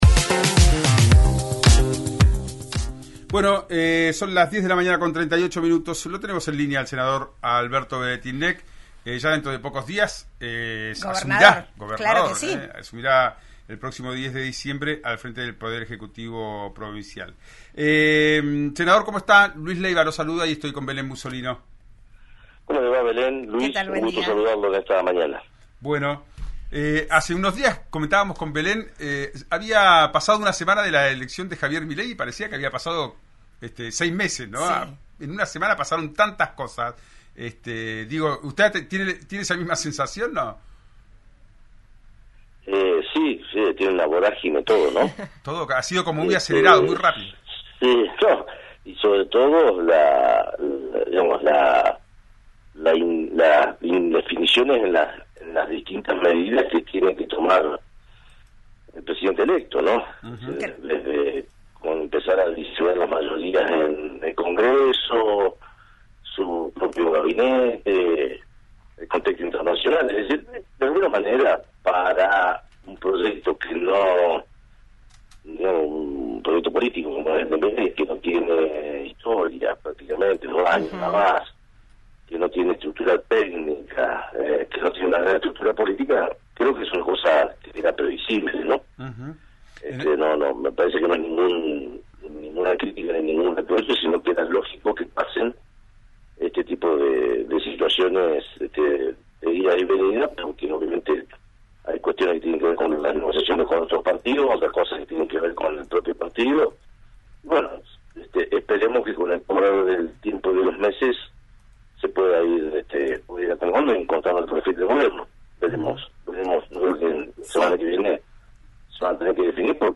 El futuro gobernador de Río Negro, Alberto Weretilneck, dialogó con RÍO NEGRO RADIO y dejó algunos títulos, en la previa a su asunción provincial. En la entrevista, confirmó que Alejandro Palmieri será quién se hará cargo de la Agencia de Recaudación Tributaria, opinó sobre el mandato de Arabela Carreras, reflexionó sobre las propuestas de Javier Milei y sobre qué hará con el bloque de partidos provincialistas que conformó en el Congreso.